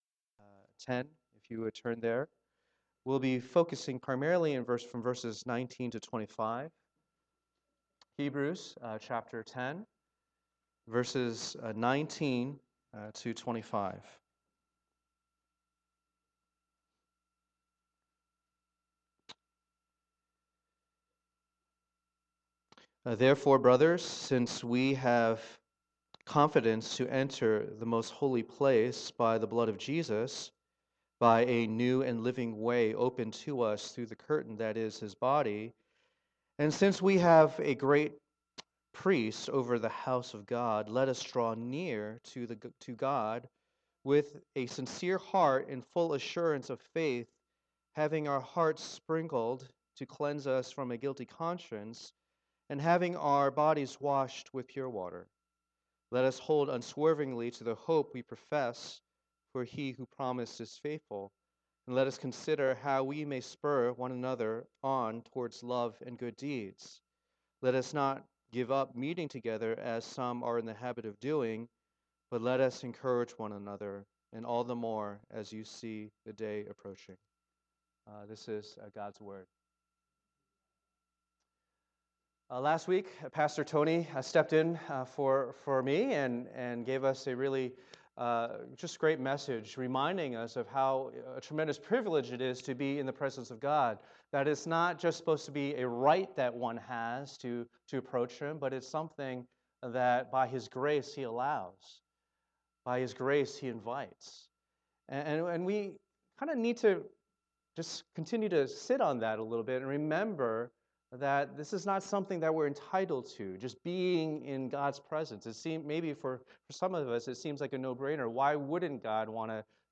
Passage: Hebrews 10:19-25 Service Type: Lord's Day